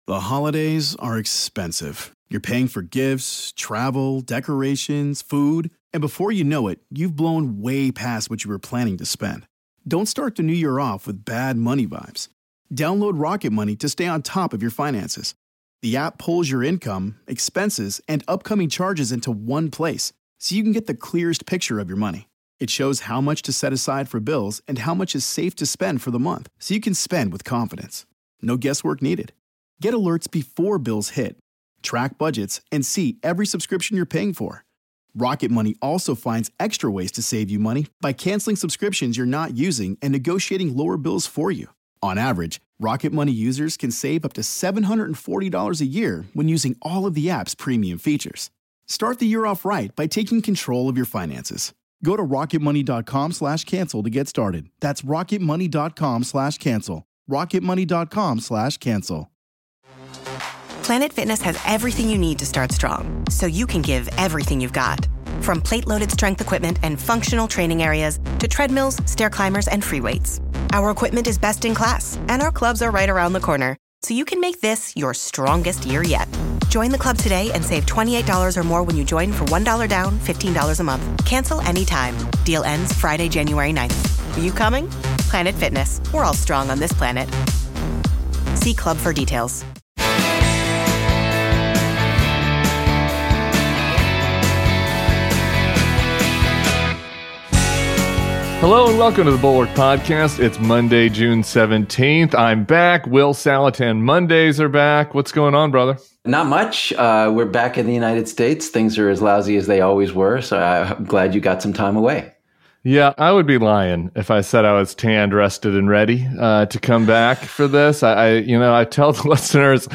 Will Saletan joins Tim Miller, who is back from vacation.